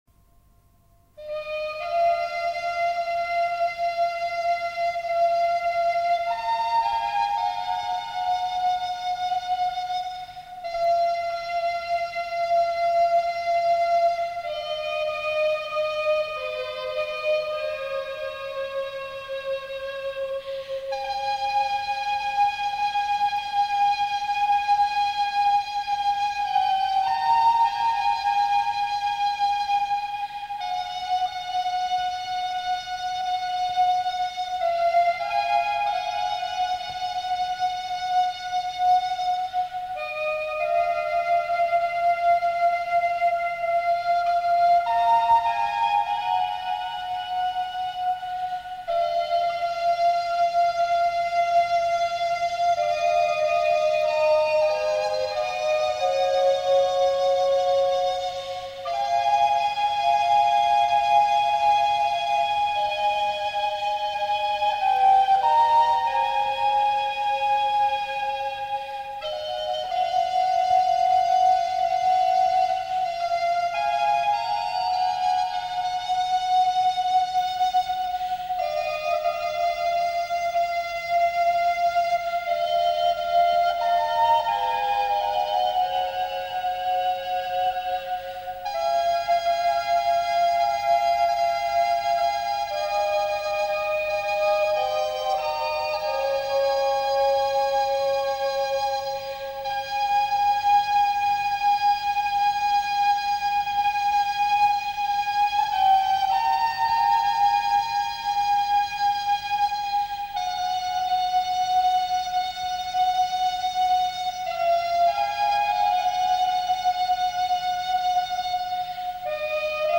soulful interpretations